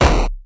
bomb.wav